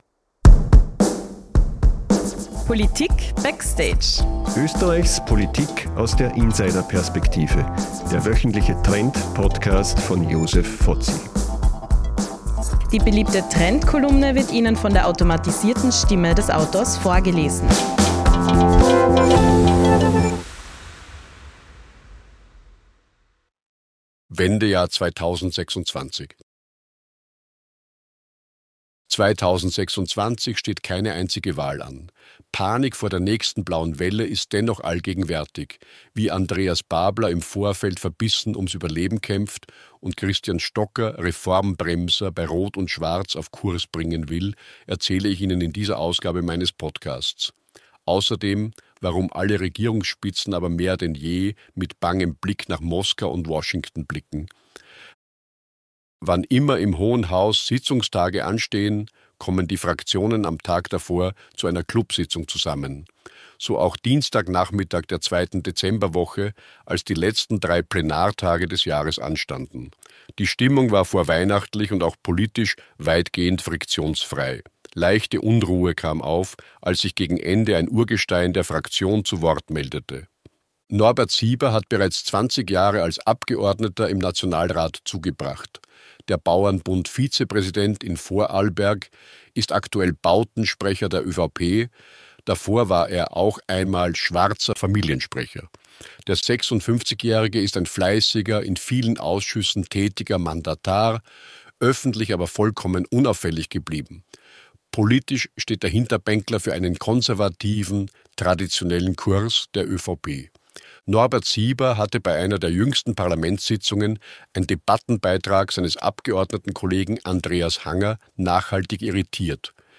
Wie jede Woche erzählt Ihnen die KI-generierte Stimme